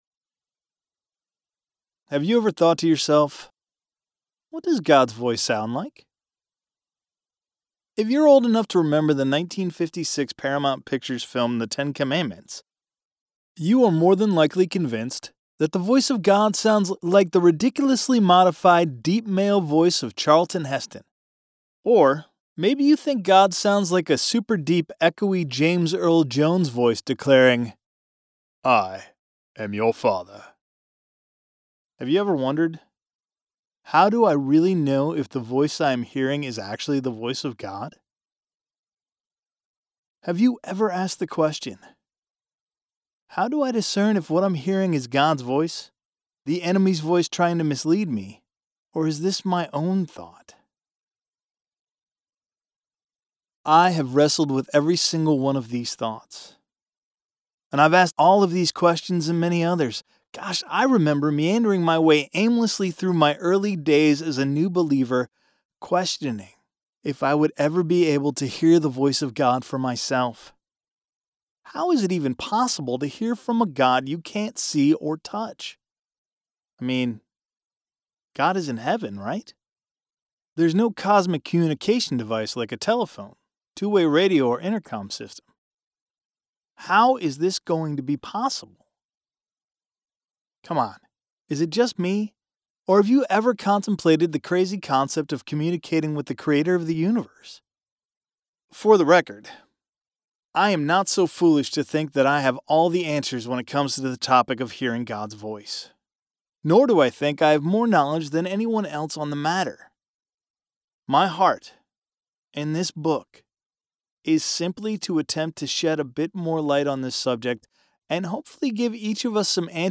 non-fiction-religion-demo.mp3